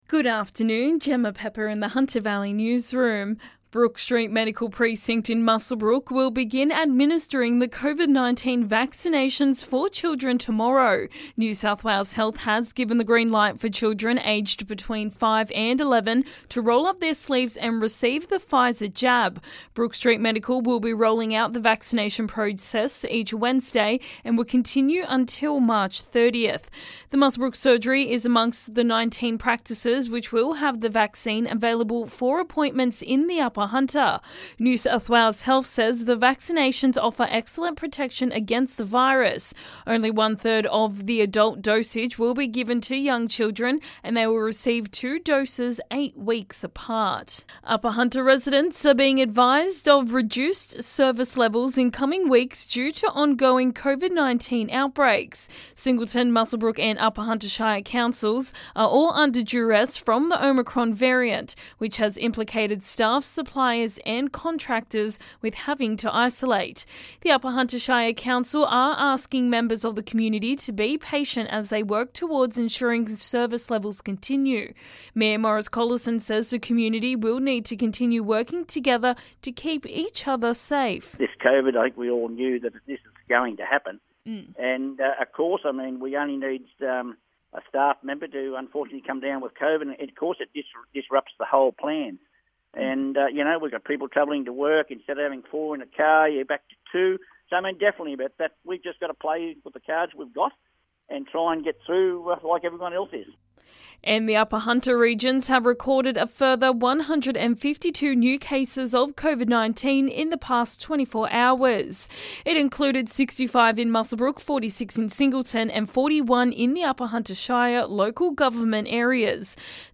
LISTEN: Hunter Valley Local News Headlines 11/01/22